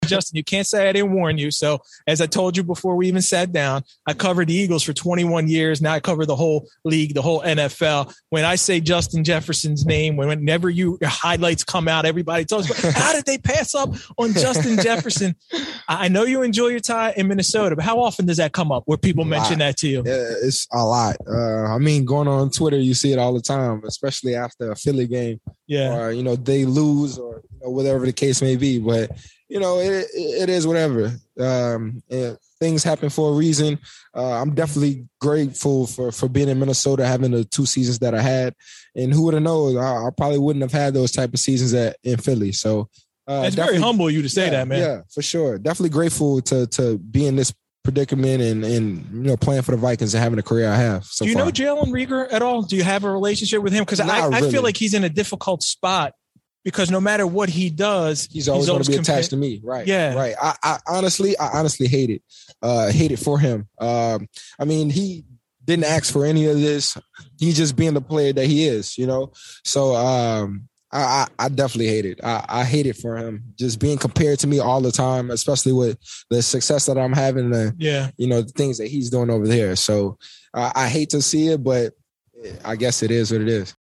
Here is Justin Jefferson’s answer: